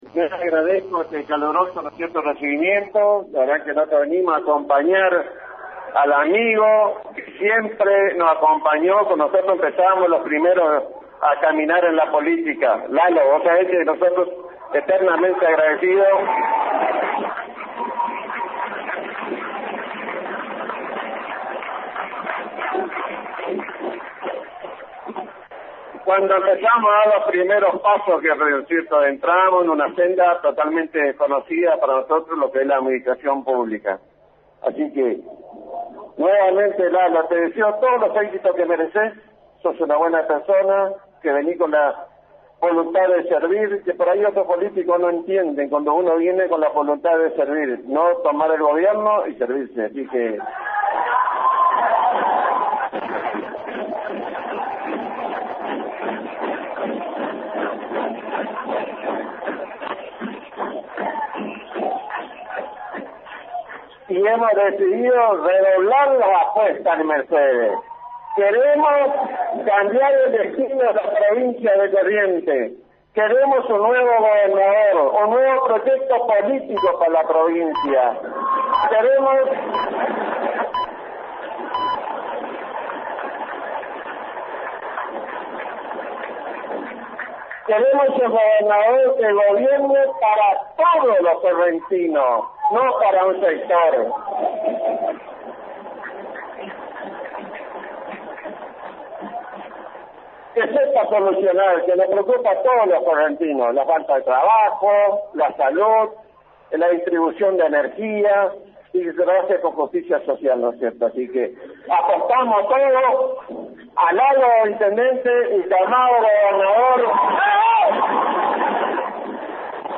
El evento que fue periodísticamente cubierto en vivo y en directo por LT25 Radio Guarani reprodujo los discursos del acto político realizado en un conocido salón de fiestas de calles Irastorza y Rodríguez Peña de ésta ciudad.
Cemborain: Discurso completo
cembo_discurso_lanzamiento.mp3